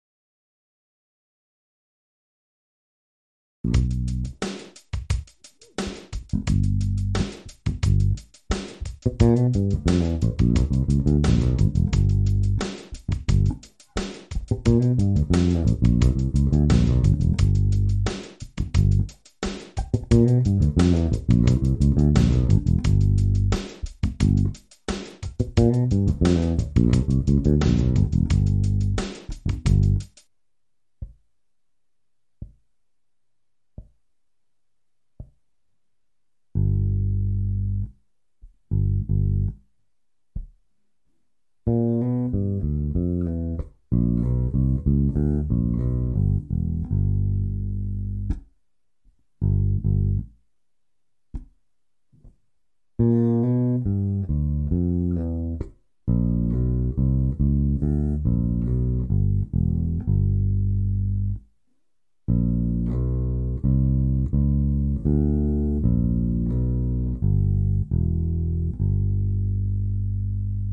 L195 Blues bass run i G7
L195-G7-bluesy-bass-fill.mp3